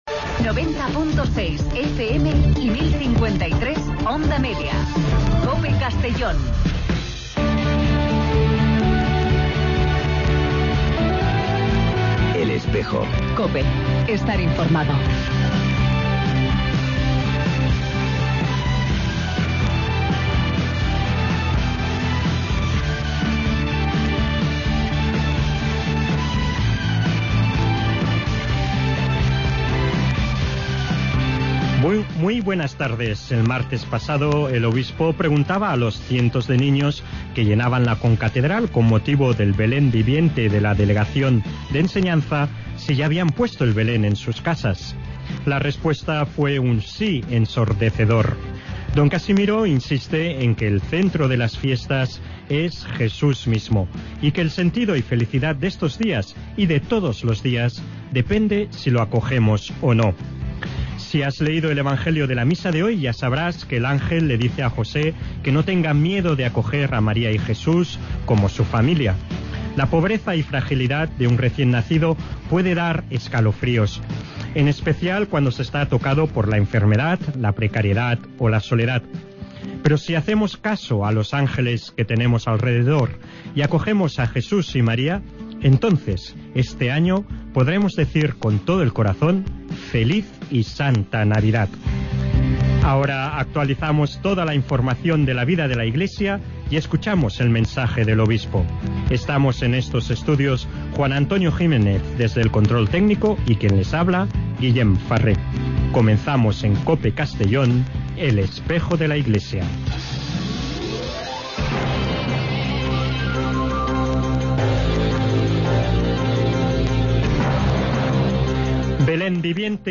Redacción digital Madrid - Publicado el 18 dic 2015, 17:54 - Actualizado 18 mar 2023, 06:20 1 min lectura Descargar Facebook Twitter Whatsapp Telegram Enviar por email Copiar enlace El programa de radio de la diócesis de Segorbe-Castellón. Con entrevistas, información y el mensaje semanal de monseñor Casimiro López Llorente.